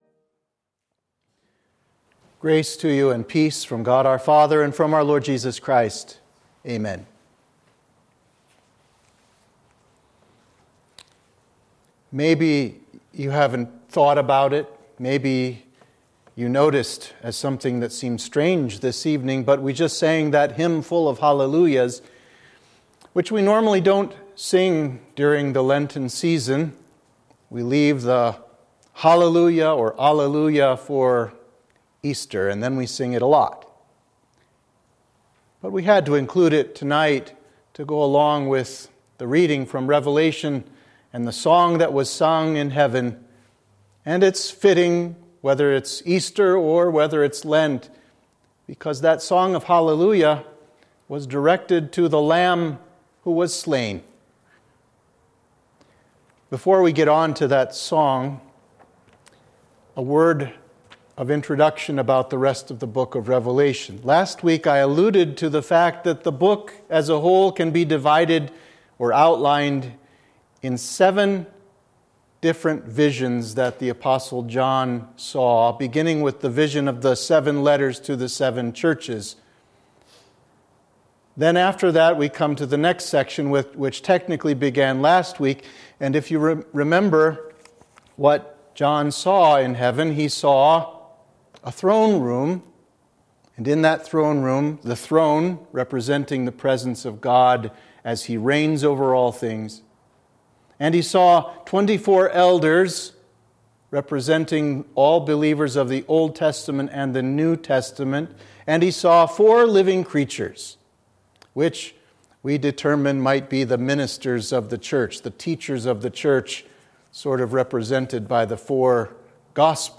Sermon (audio)